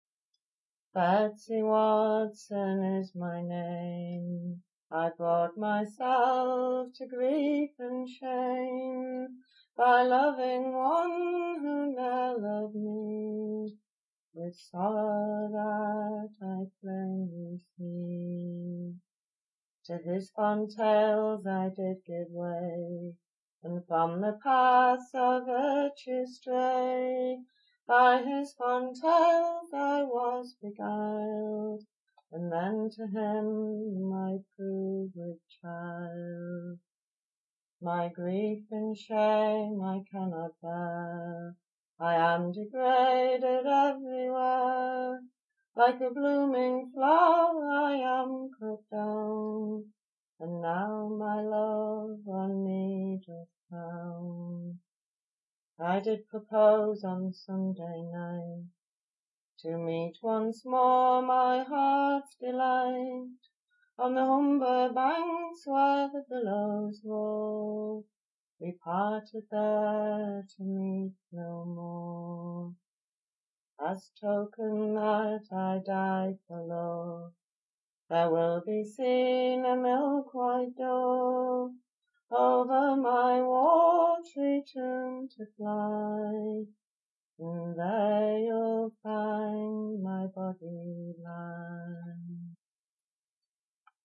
Hull
Courtship
F lydian
5/8 and various